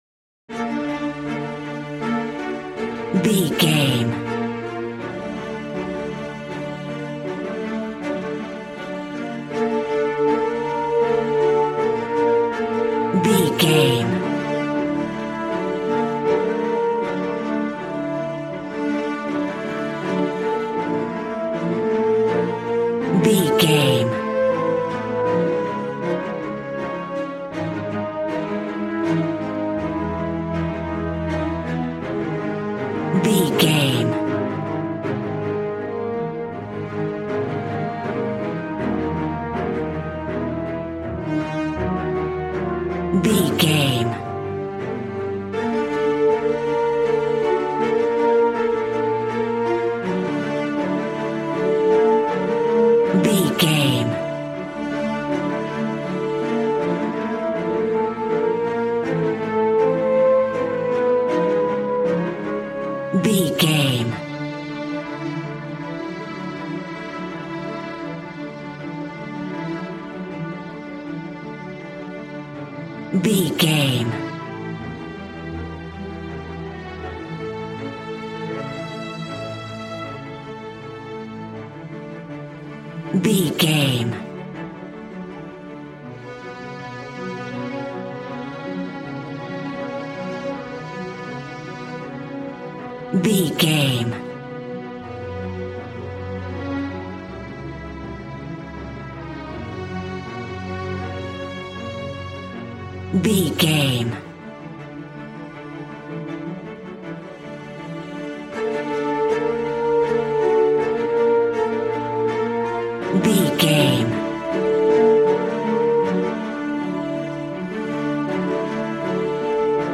A classical music mood from the orchestra.
Regal and romantic, a classy piece of classical music.
Ionian/Major
D
regal
cello
violin
strings